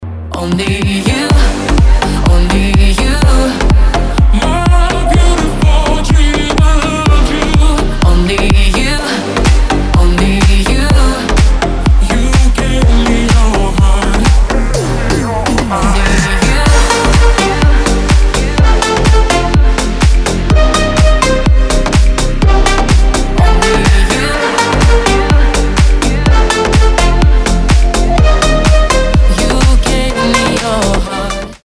• Качество: 320, Stereo
красивые
женский голос
slap house
Стиль: deep house